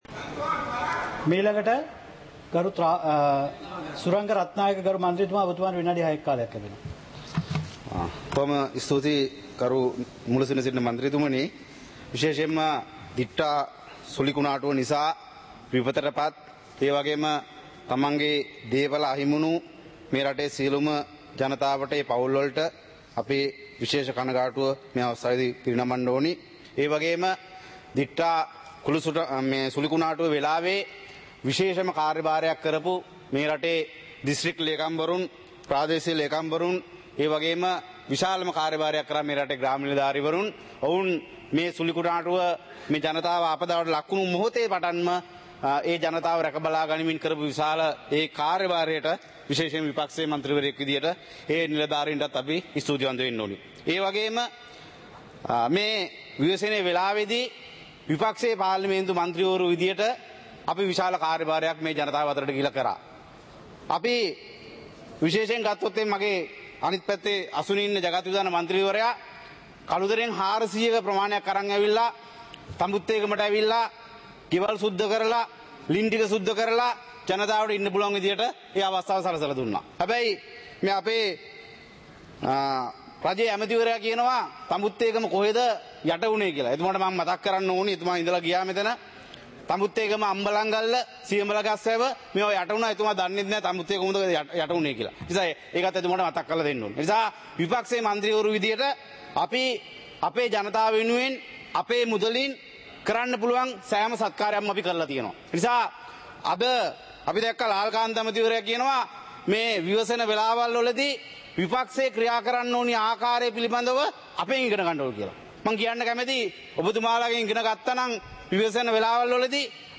සභාවේ වැඩ කටයුතු (2025-12-19)